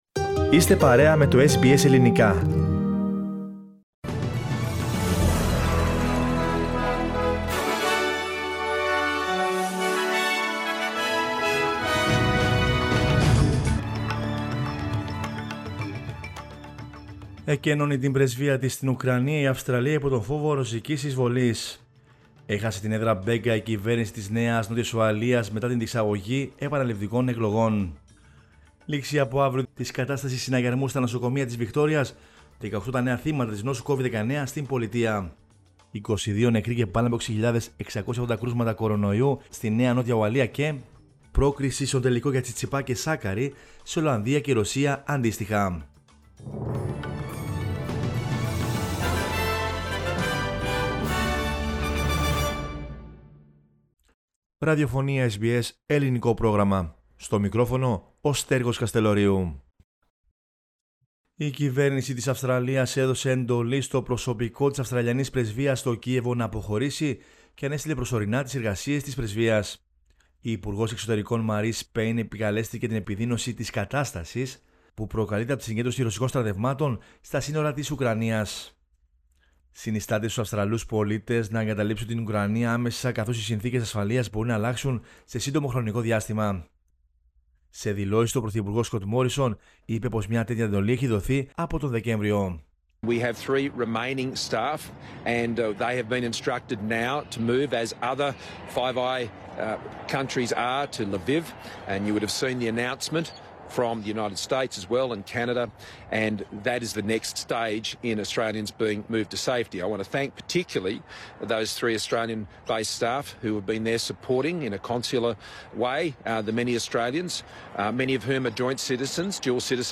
News in Greek from Australia, Greece, Cyprus and the world is the news bulletin of Sunday 13 February 2022.